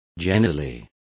Complete with pronunciation of the translation of generally.